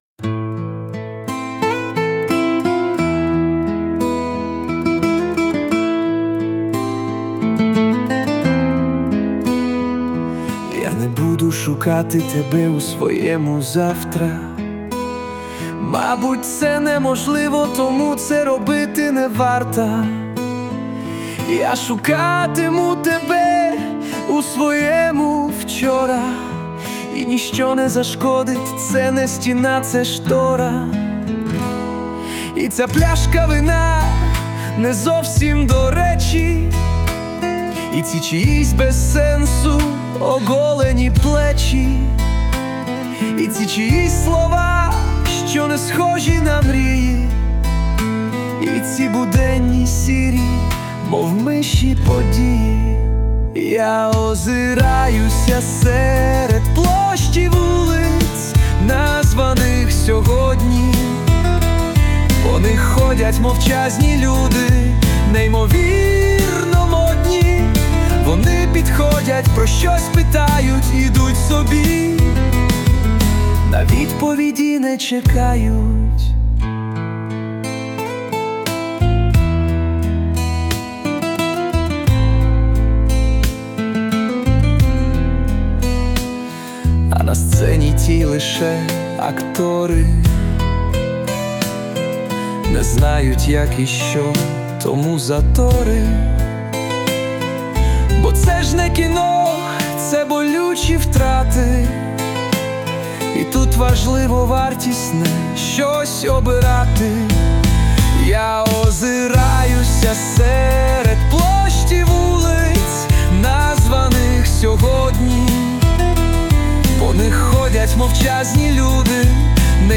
У виконанні використано допомогу SUNO
СТИЛЬОВІ ЖАНРИ: Ліричний
Чарівна пісня, хоч і сумна.